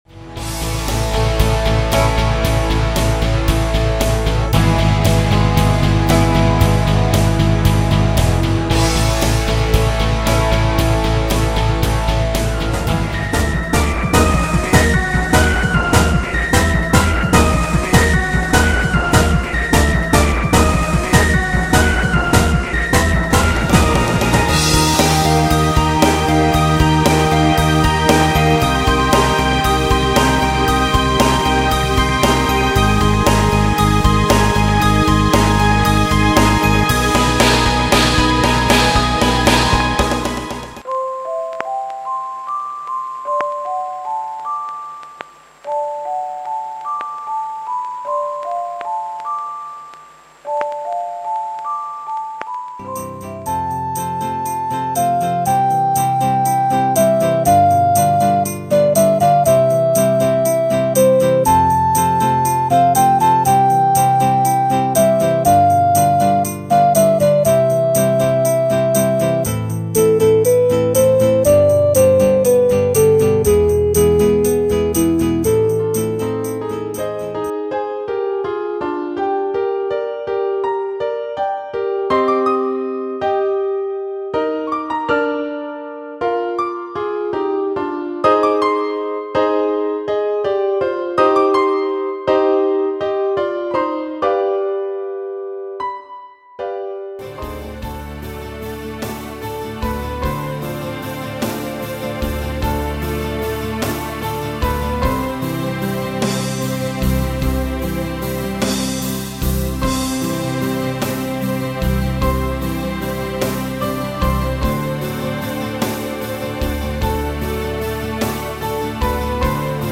オリジナル音楽ＣＤ・即売会価格５００円・発行数：初版５０部